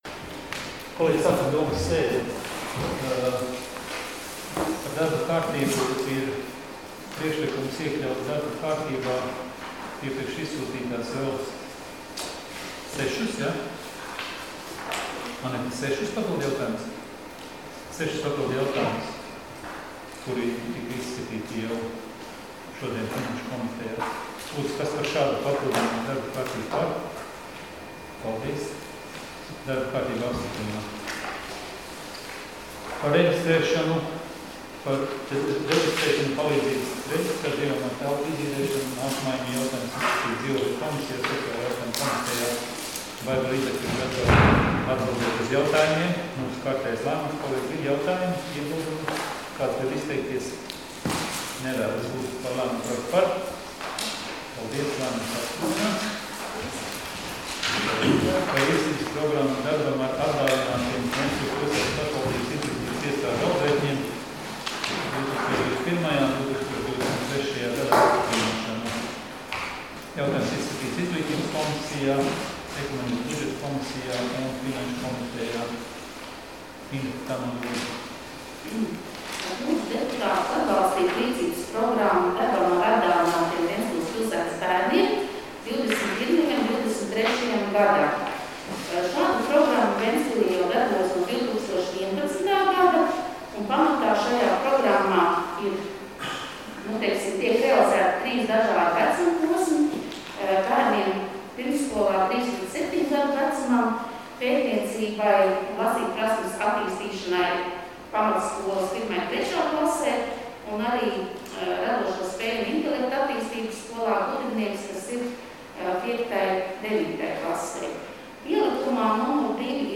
Domes sēdes 17.12.2020. audioieraksts